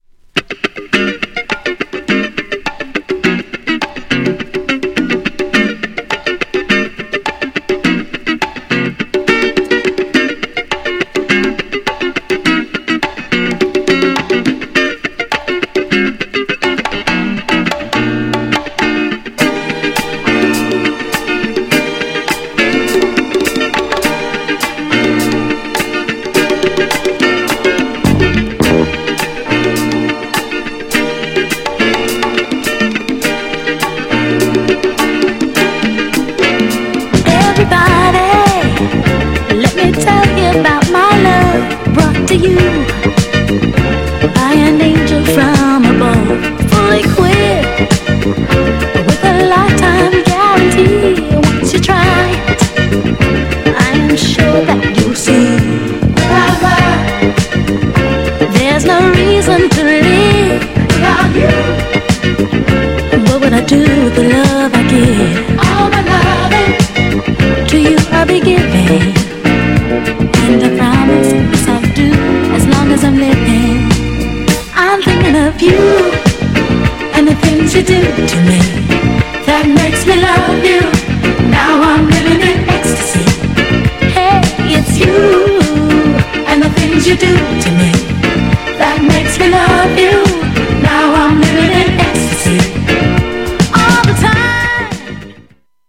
GENRE Dance Classic
BPM 116〜120BPM